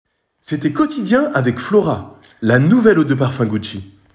Version 2 dynamique